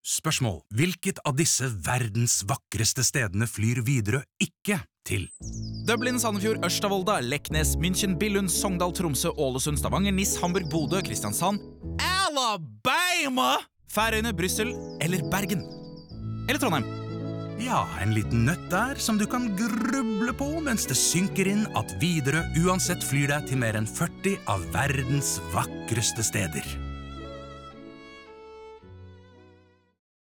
Her blir vi dratt inn i ekte quiz-på-radio-stil og begynner umiddelbart å lure på hva som er riktig svar. Så viser det seg at spotten selv avslører dette gjennom overdrevne artigheter og tøysete sørstatsparodier (noe juryen forøvrig er svak for.)